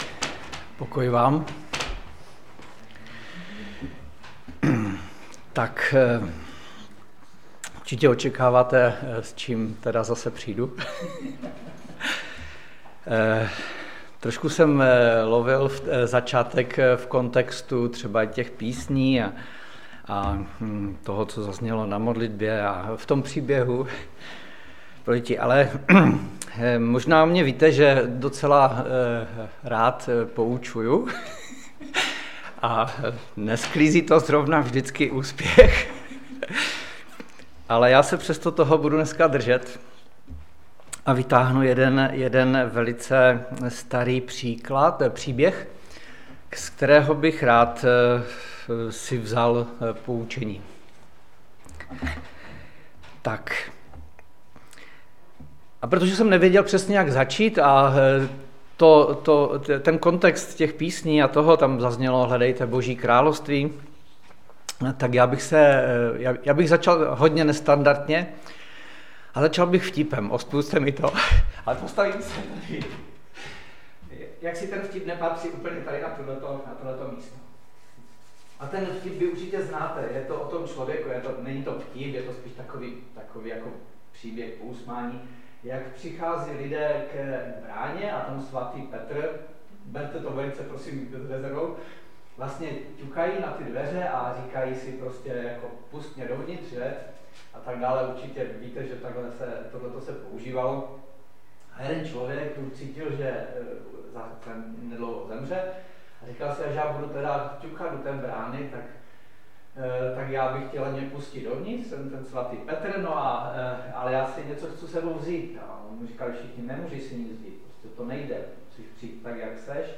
Kázání sboru CASD Vrbno pod Pradědem